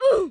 roblox-death-sound_PWcGc5Q.mp3